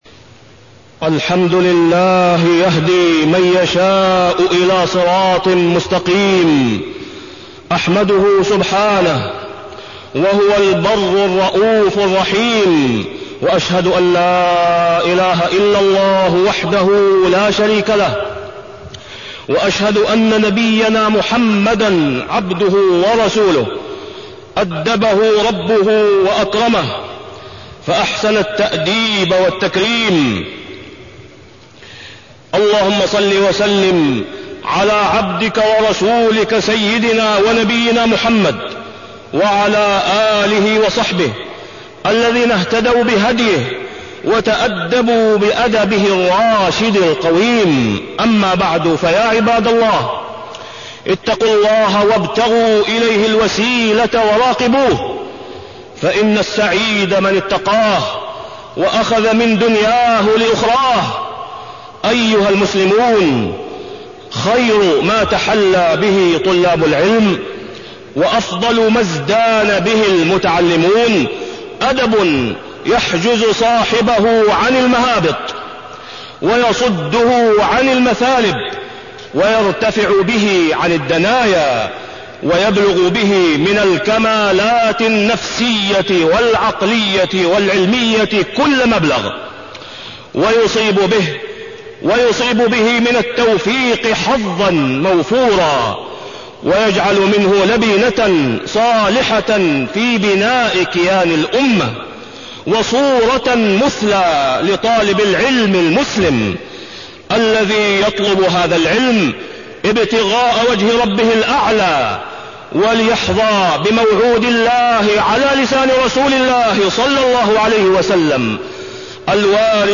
تاريخ النشر ١٣ رجب ١٤٢٤ هـ المكان: المسجد الحرام الشيخ: فضيلة الشيخ د. أسامة بن عبدالله خياط فضيلة الشيخ د. أسامة بن عبدالله خياط آداب طالب العلم